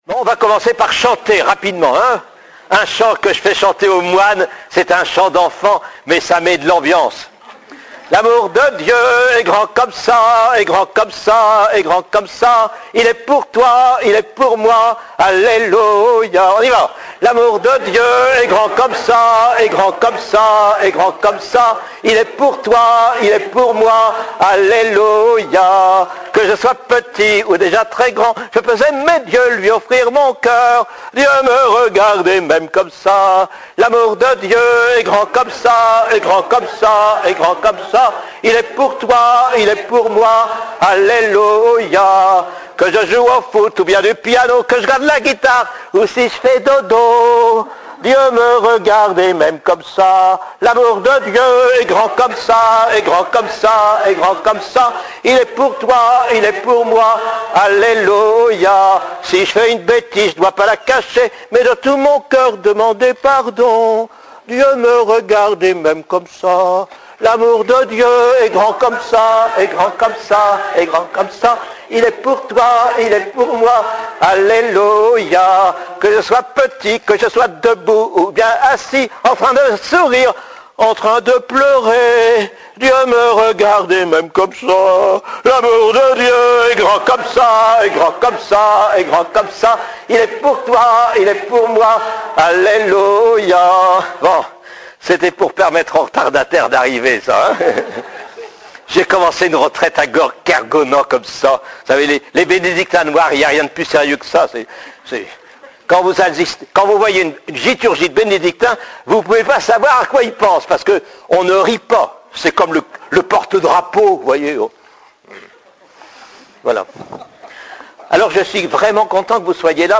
Attention : La qualit� technique de cet enregistrement n'est pas tr�s bonne. 2�me session des familles organis�e par la communaut� de l'Emmanuel Paray-le-Monial du 31 juillet au 5 ao�t 2010 Parcours Pour une foi mieux assur�e Enregistr� le 1 ao�t 2010.